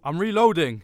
Voice Lines / Barklines Combat VA
Marcel reloading.7.wav